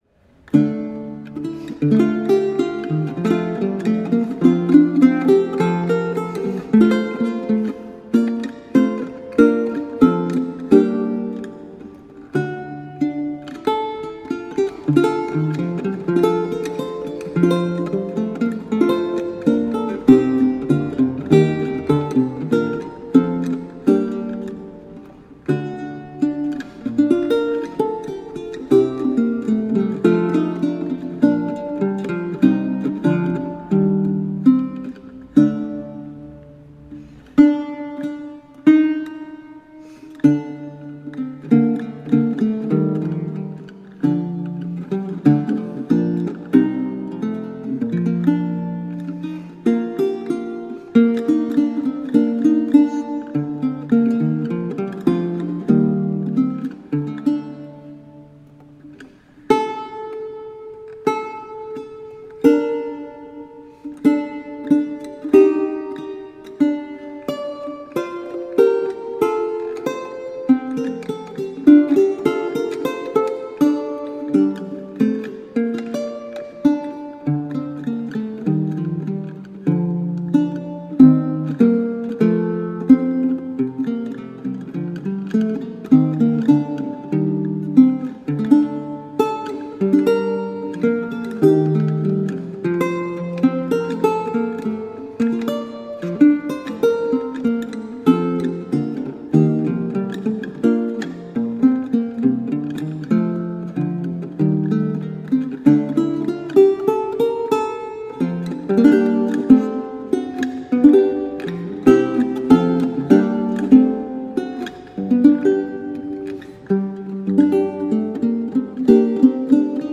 Vihuela, renaissance and baroque lute.
Classical, Renaissance, Instrumental, Lute